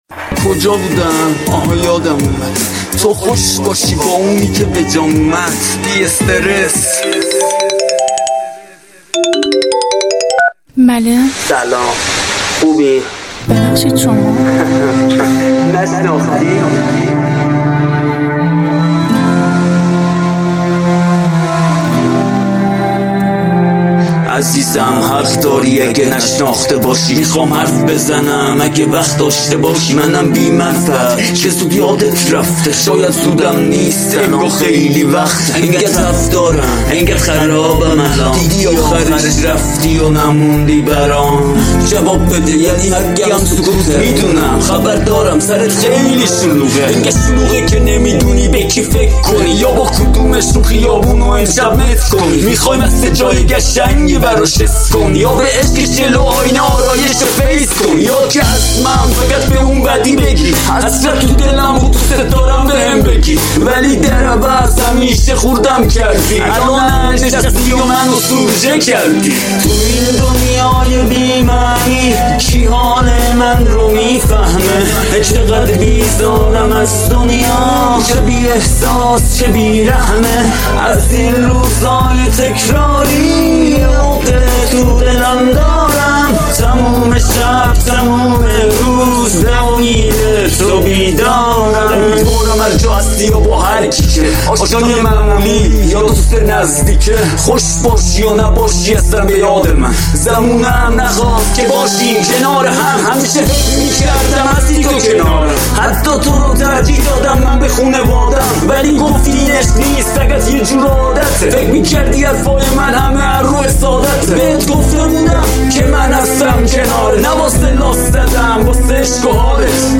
Rap and hip -hop genre